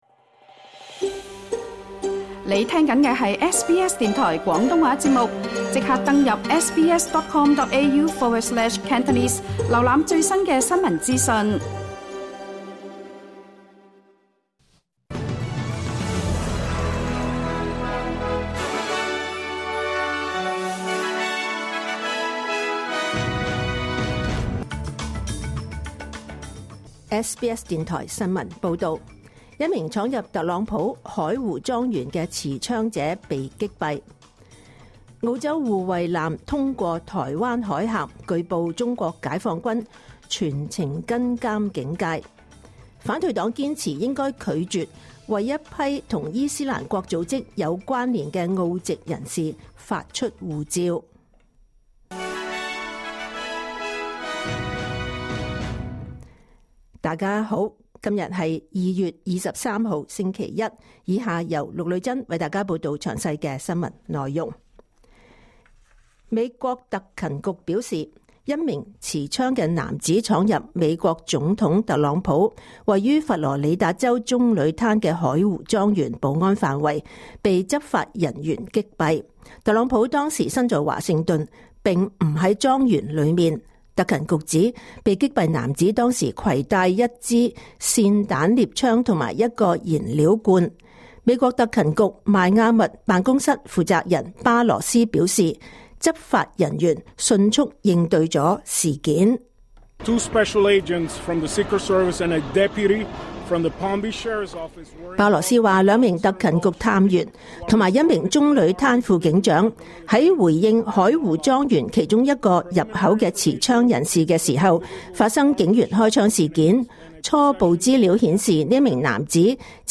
2026 年 2 月 23 日SBS廣東話節目九點半新聞報道。